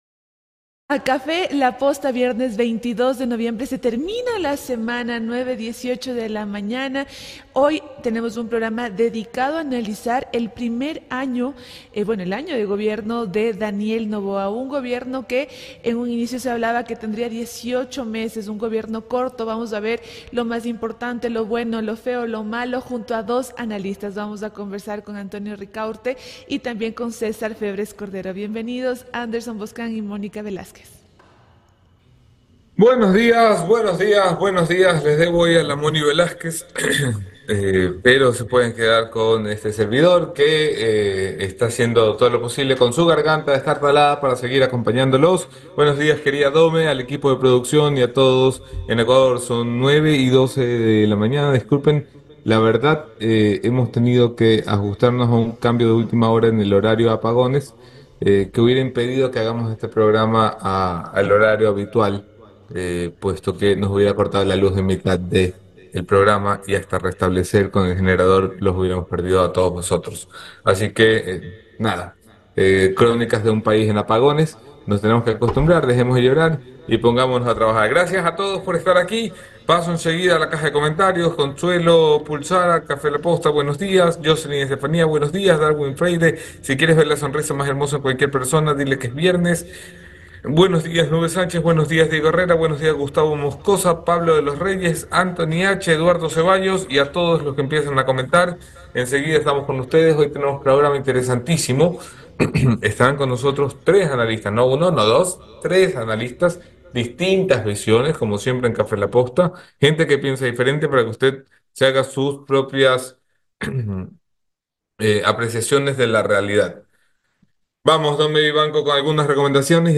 Entrevistas exclusivas con el Presidente de la Asamblea, Henry Kronfle, la viceministra de Economía Ana Cristina Avilés y la ‘vice’ del PCCS, Mishelle Calvache.